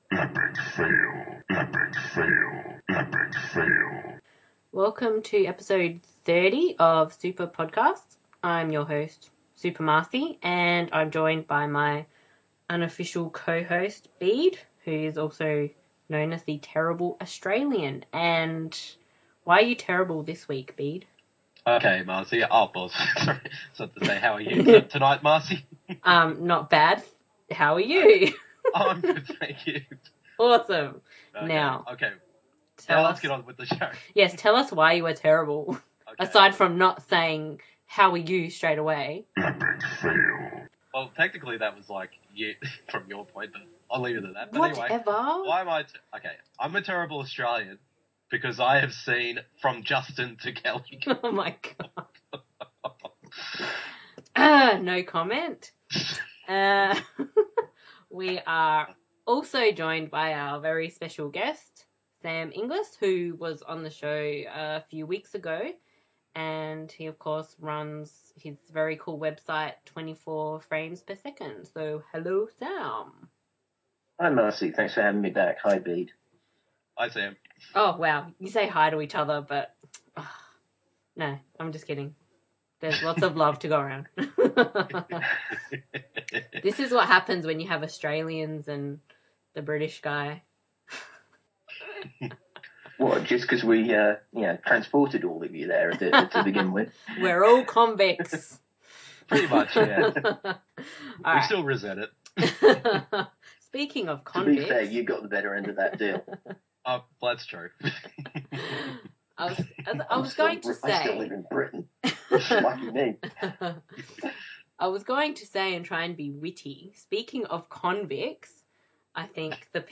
Prepare to listen to the two Aussies and the Brit (that could be the start of a joke) as they delve into some truly awful titles.